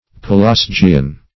Search Result for " pelasgian" : The Collaborative International Dictionary of English v.0.48: Pelasgian \Pe*las"gi*an\, Pelasgic \Pe*las"gic\, a. [L. Pelasgus, Gr.